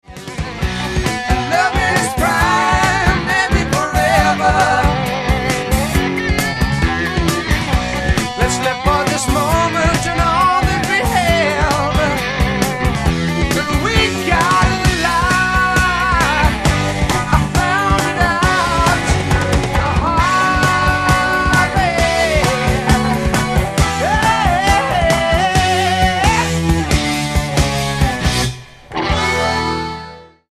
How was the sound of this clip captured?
Recorded and mixed at Phase One Studios, Toronto, Canada.